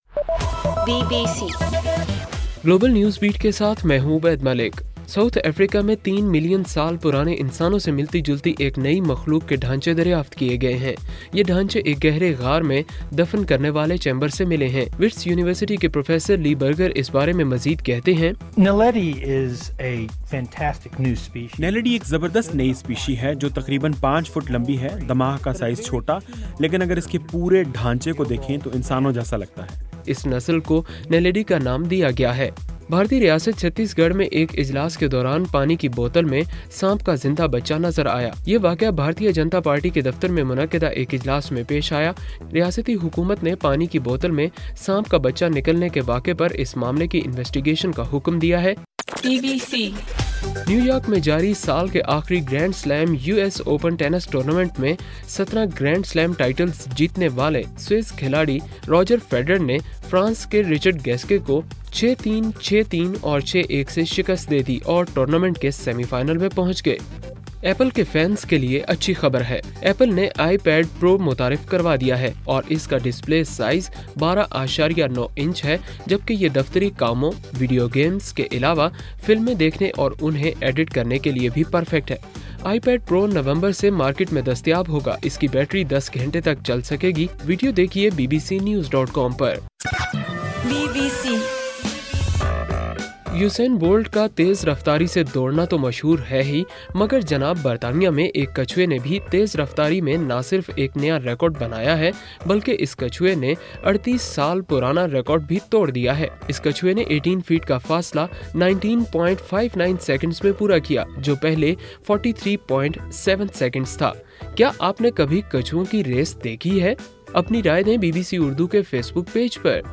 ستمبر 10: رات 8 بجے کا گلوبل نیوز بیٹ بُلیٹن